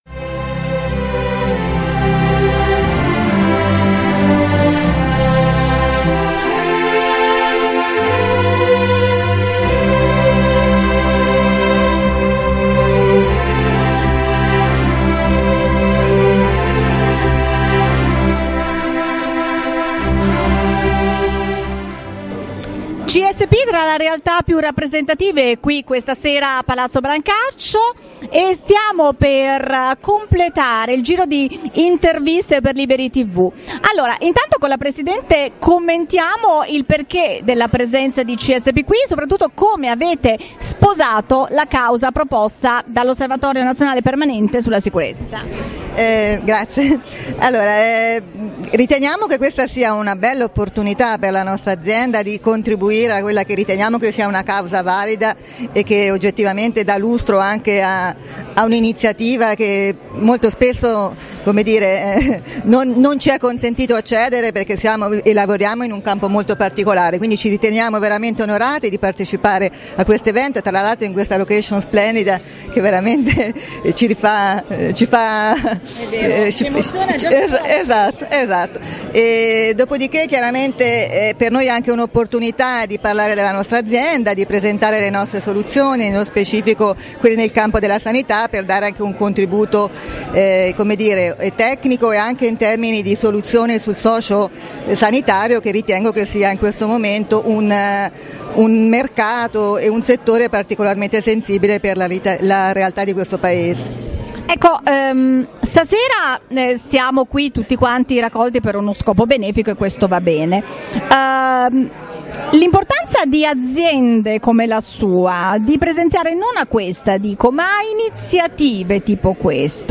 Serata di Gala di Beneficienza organizzata da ONPS (Osservatorio Nazionale Permanente sulla Sicurezza). Roma, 11 dicembre 2015 Palazzo Brancaccio.